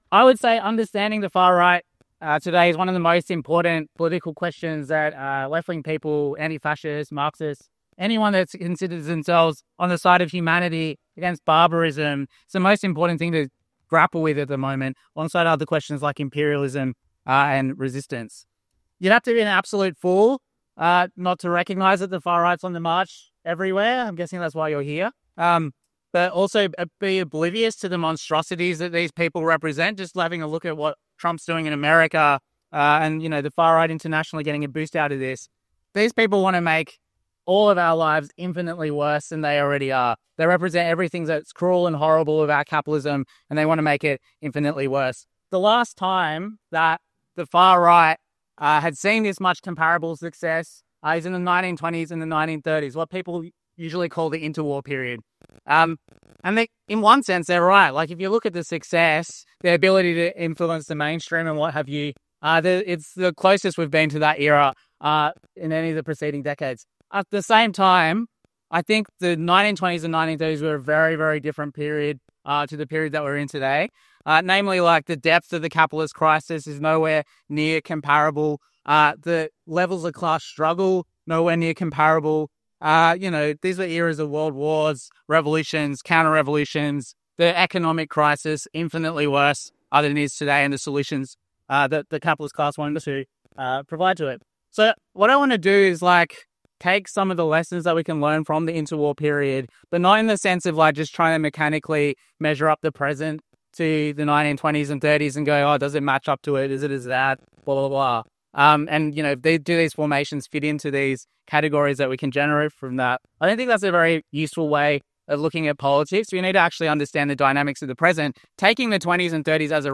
Understanding the far-right threat Marxism 2025 Play talk Download The far right is on the march everywhere.